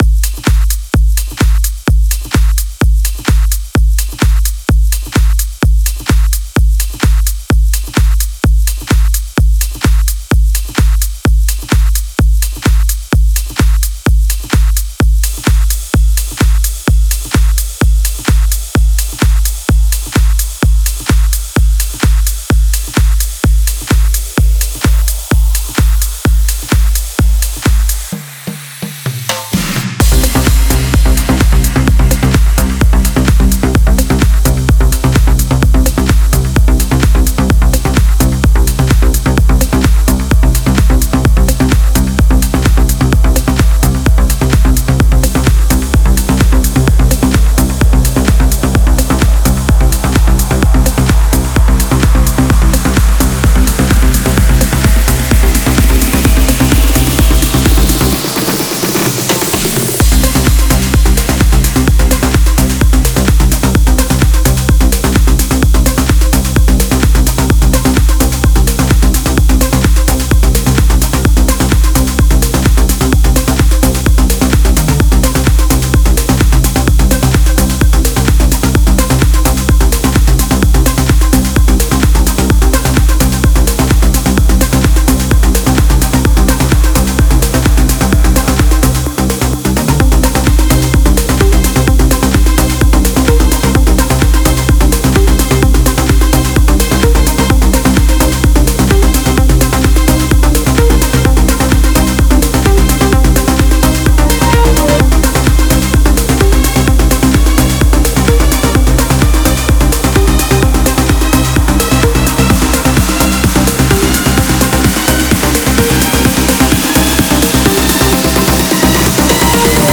Стиль: Vocal Trance